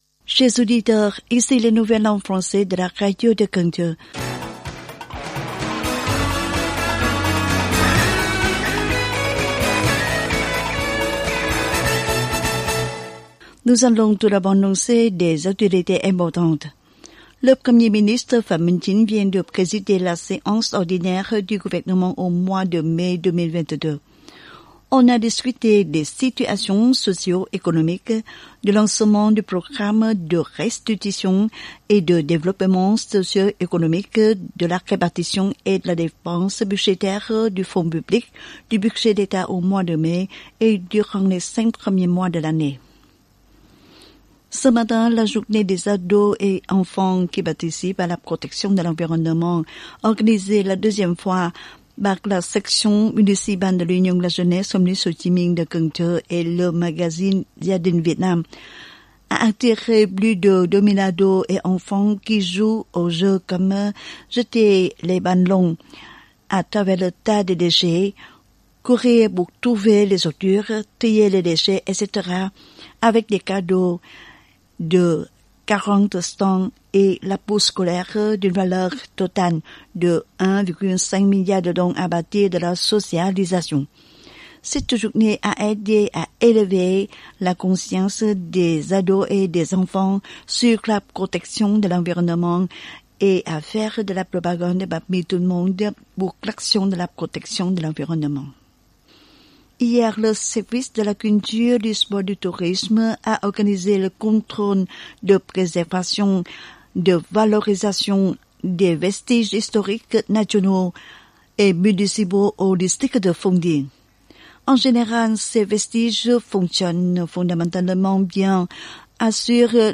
Bản tin tiếng Pháp 4/6/2022